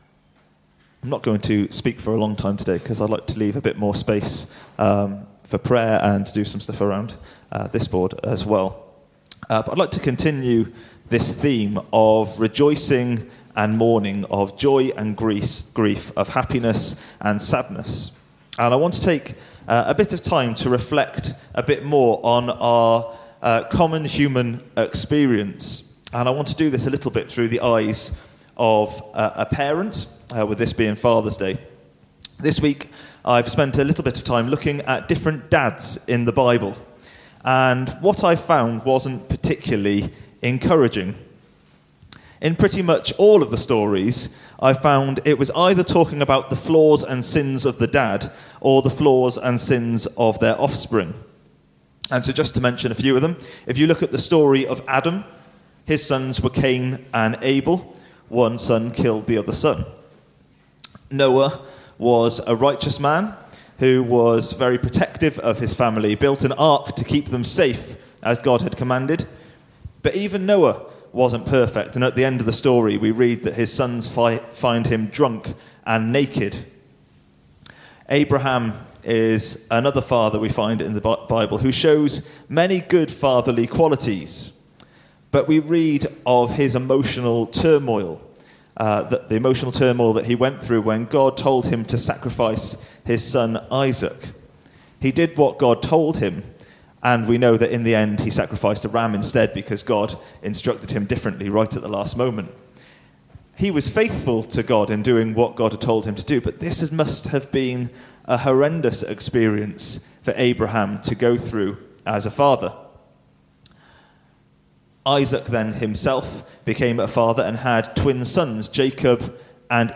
Talks